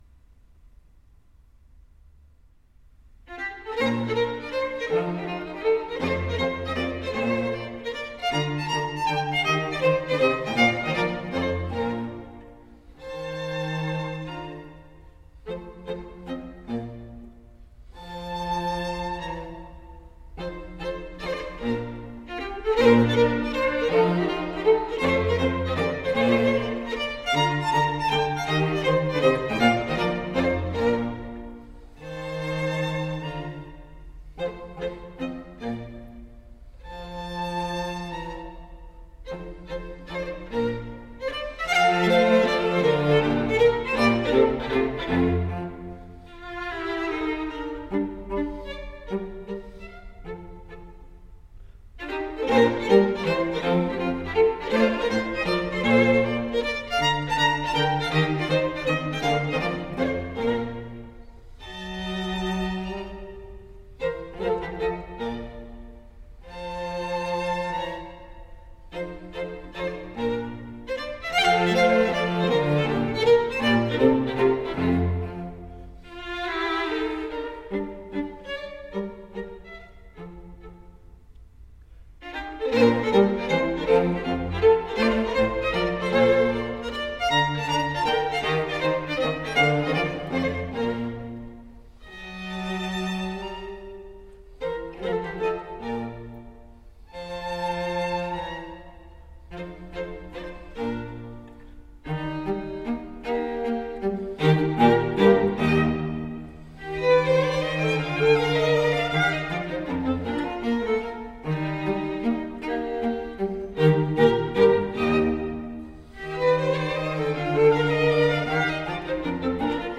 A group of three musicians.
Sonate (Trio) in B flat
Menuetto